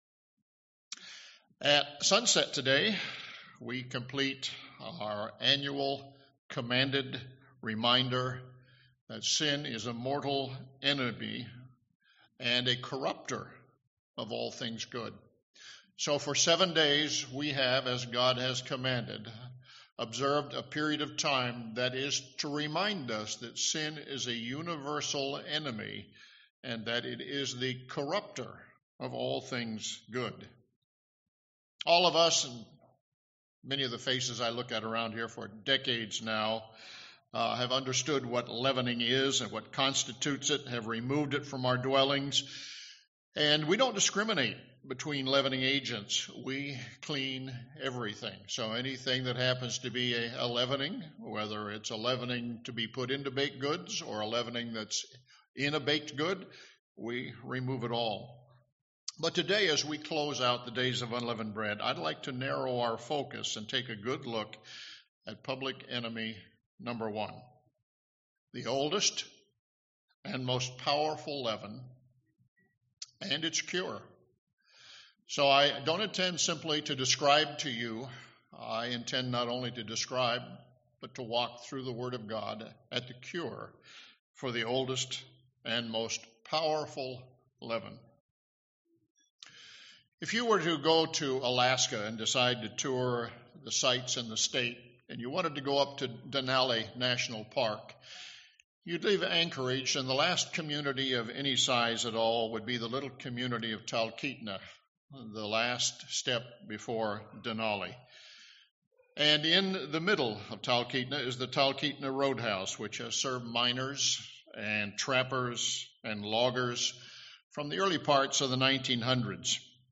Scripture shows plainly that one spiritual sin has existed from the fall of Lucifer and has been dominant throughout history. It does thankfully have an antidote which this sermon will explore at length.